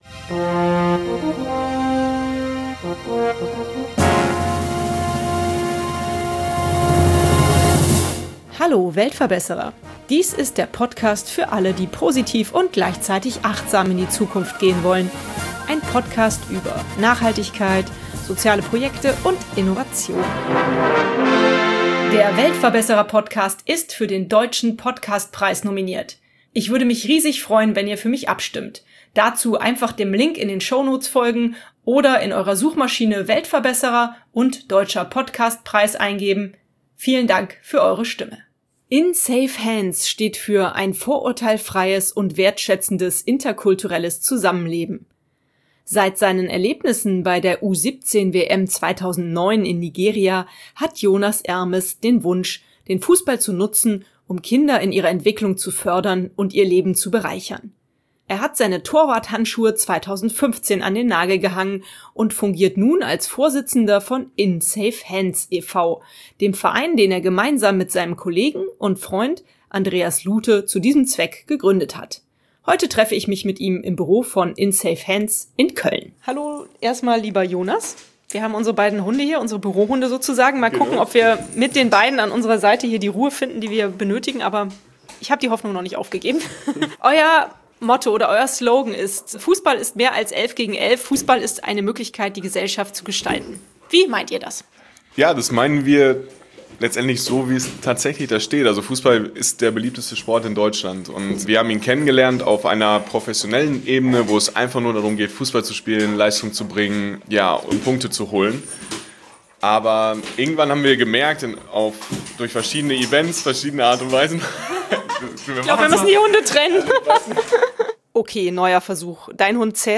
Heute treffe ich mich mit ihm im Büro von „In safe hands e.V.“ in Köln Mehr